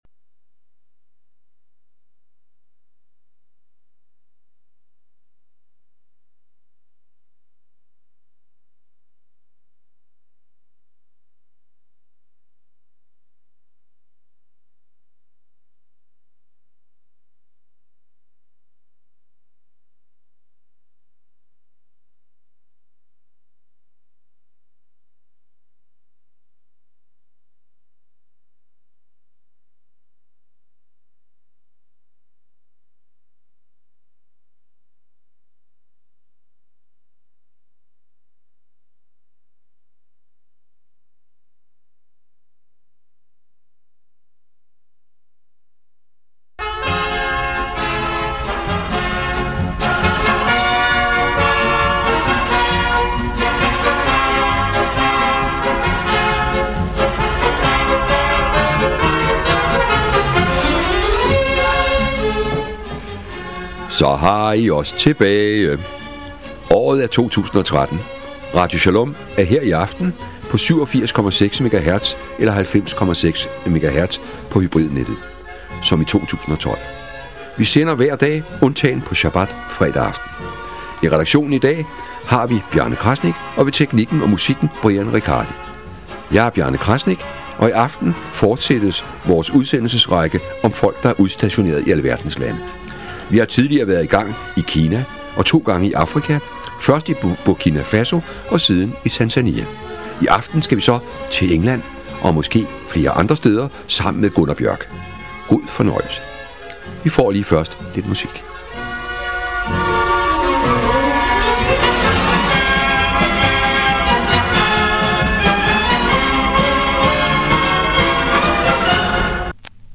Beskrivelse:Interview